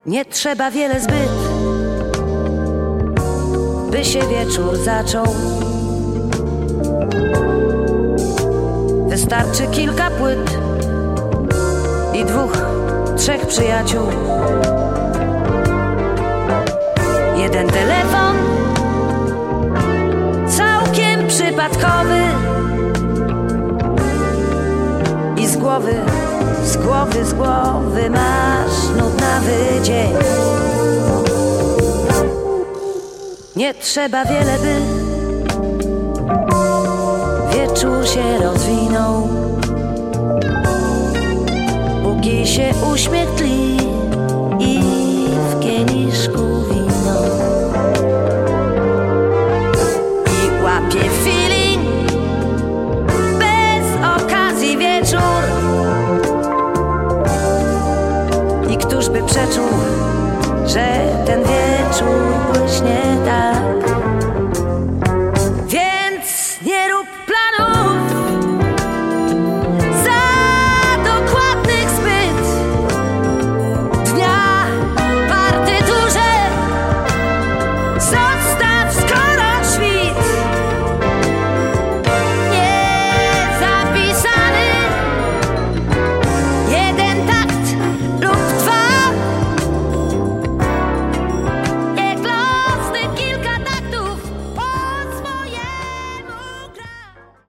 Recording Poznan- 2000
Remastering BandLab 2023
DUET   DUET
urodzona w Gdańsku wokalistka i kompozytorka.